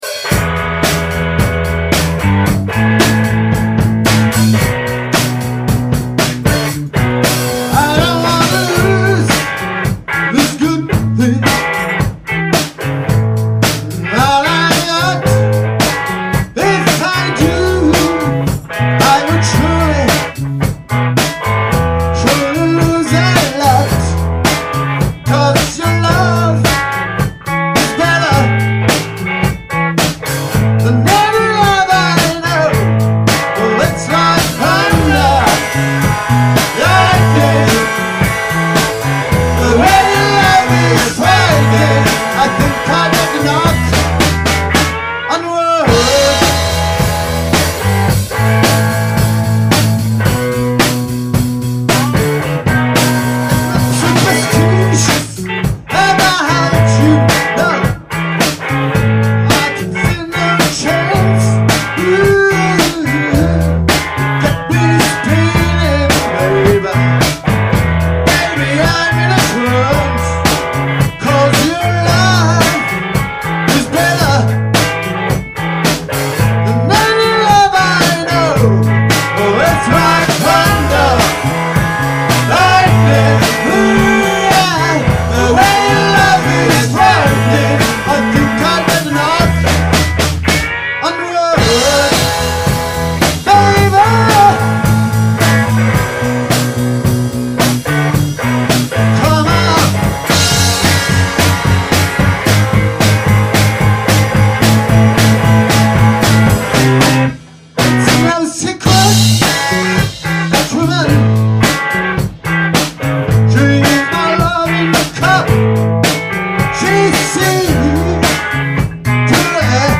Garage Soul